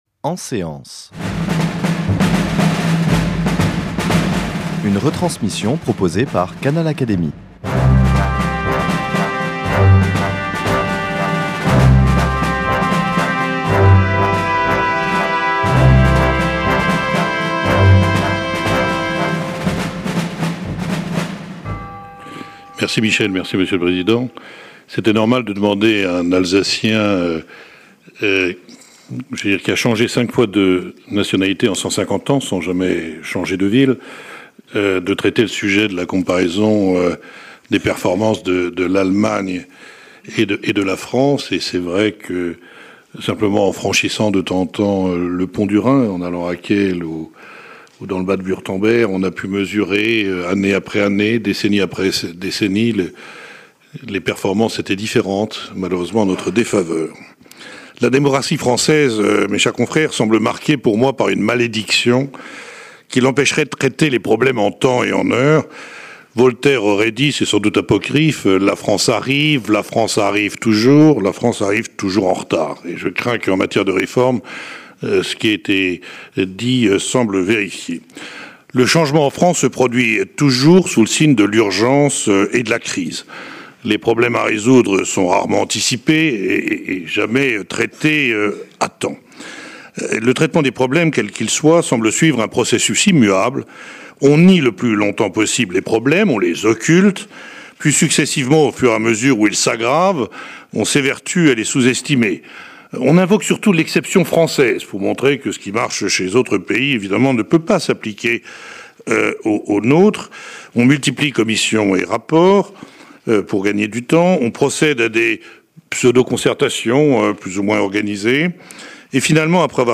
Cette intervention de l’économiste Denis Kessler, membre de l’Académie des sciences morales et politiques, inaugure le cycle de contributions 2017 de l’Académie, consacré au thème de “la réforme”.